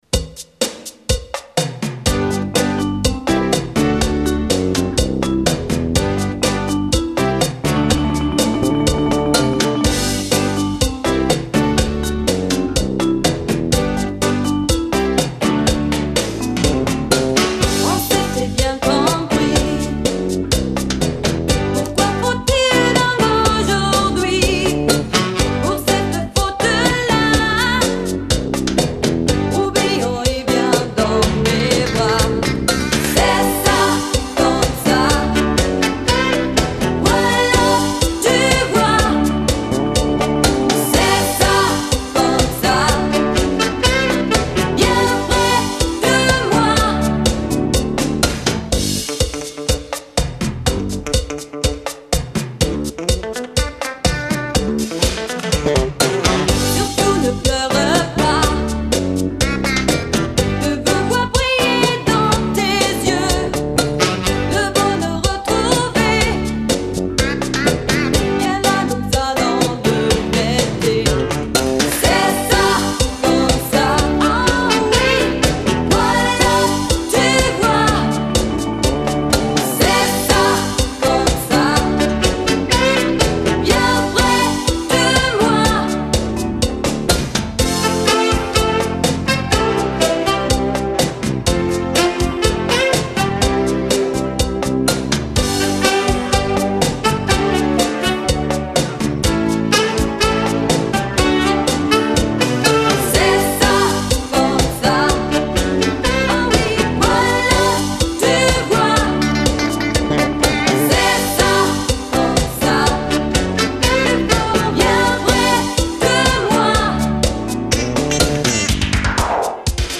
03 Cha-Cha-Cha